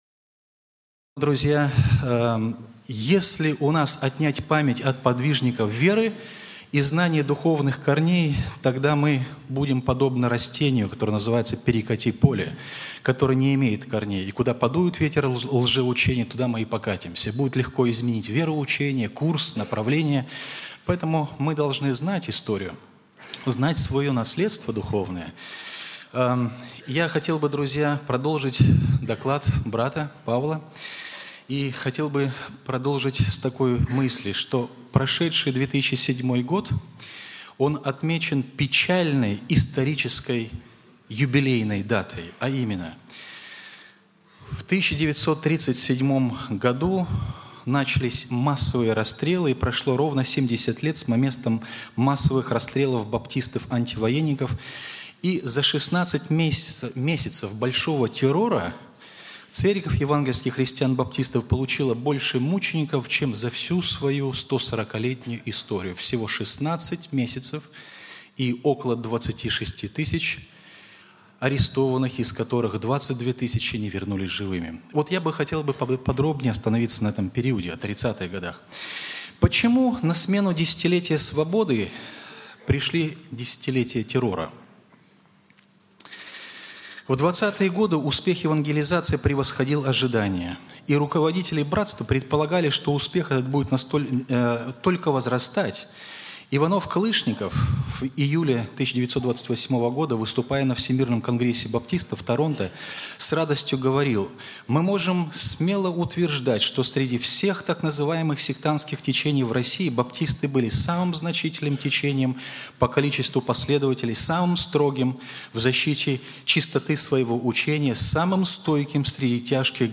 Konferentsiya po blagovestiyu